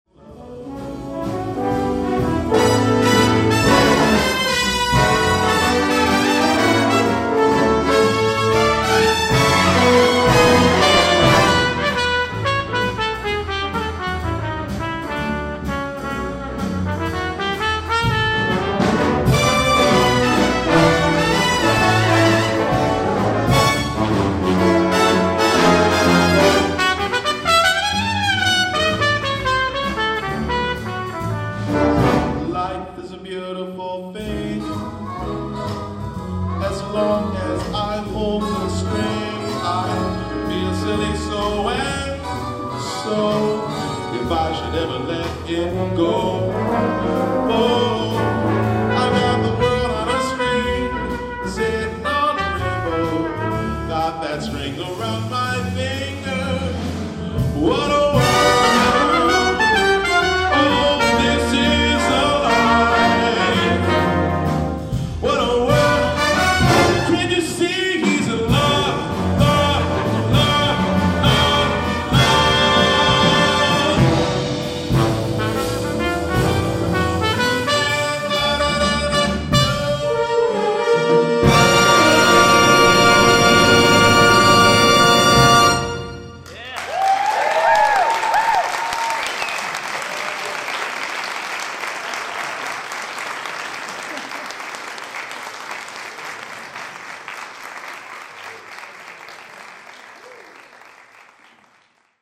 Established in 1997, Seaside Brass is a sextet of brass and percussion instrumentalists well versed in a variety of styles.